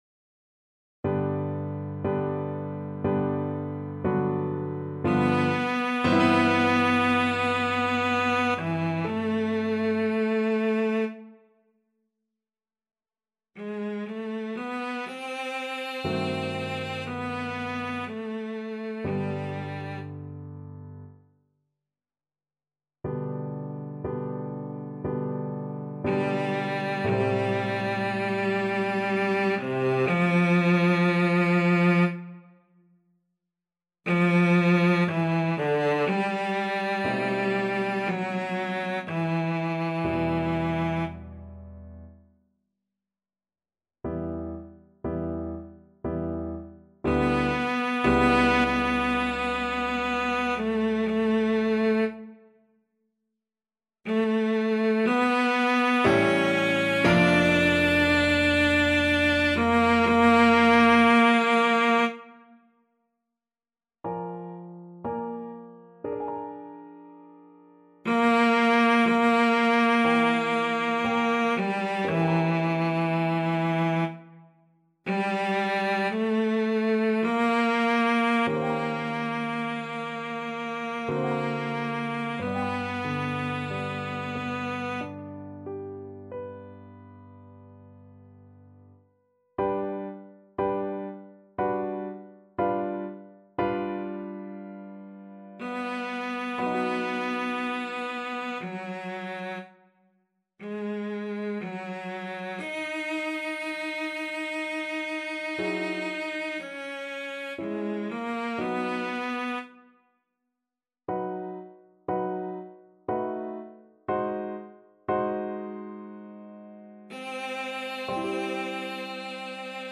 3/4 (View more 3/4 Music)
~ = 60 Langsam, leidenschaftlich
Classical (View more Classical Cello Music)